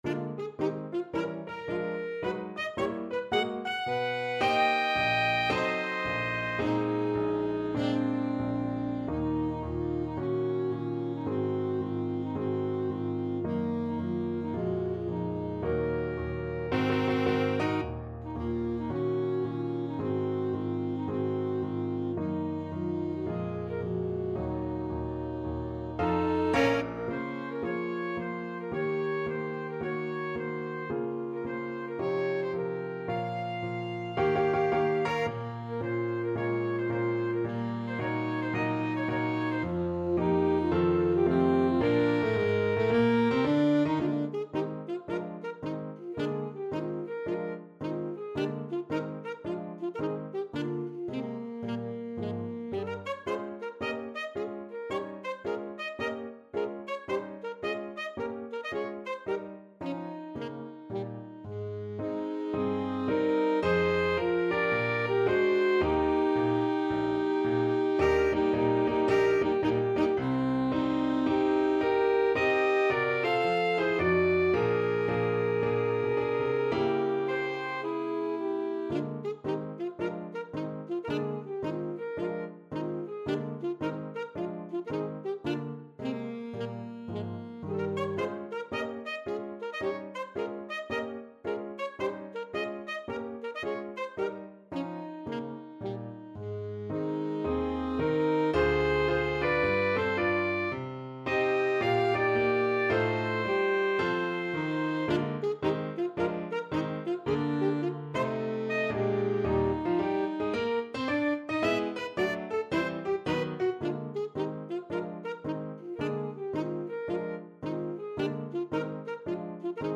Alto Saxophone 1Alto Saxophone 2Piano
4/4 (View more 4/4 Music)
Moderato =110 swung